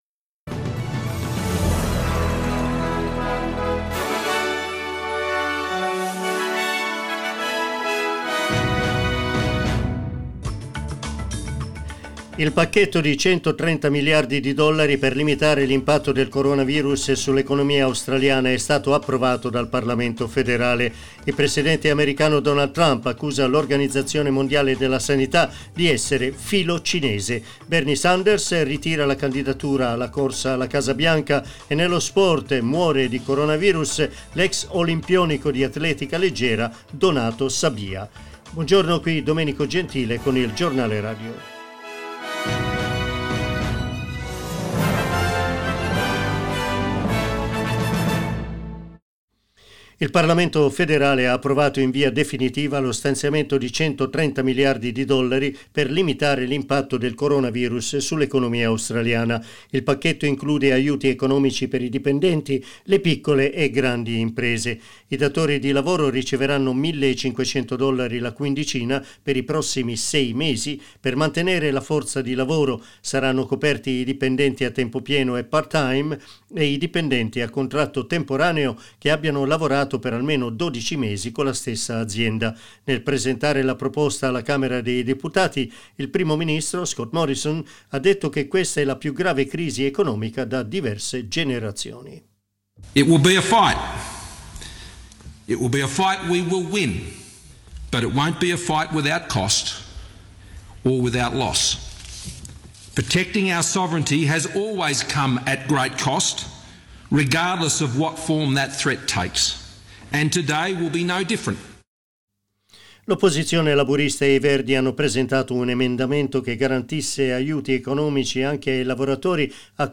Our news bulletin (in Italian).